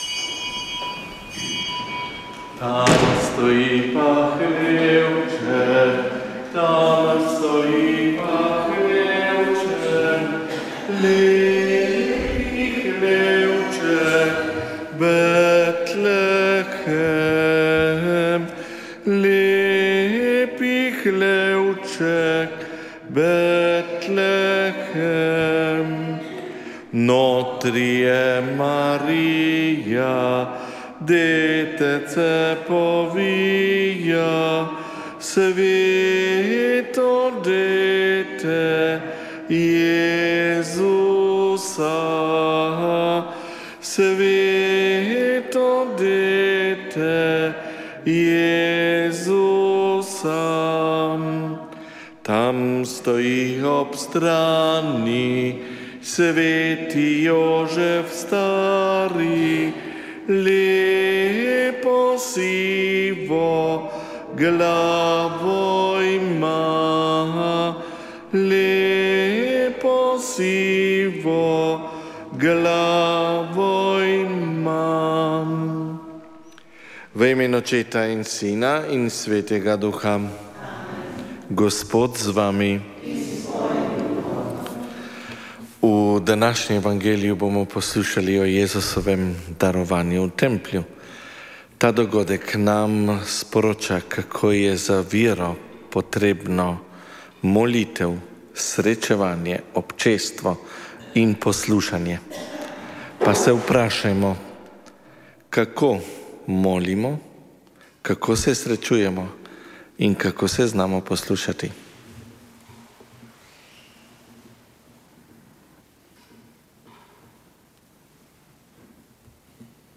Sveta maša
Sv. maša iz cerkve sv. Mihaela iz Grosuplja dne 5. 5.